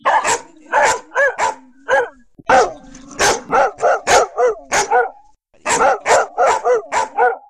جلوه های صوتی
دانلود صدای دو سگ عصبانی در حال پاس کردن از ساعد نیوز با لینک مستقیم و کیفیت بالا
برچسب: دانلود آهنگ های افکت صوتی انسان و موجودات زنده دانلود آلبوم صدای سگ از افکت صوتی انسان و موجودات زنده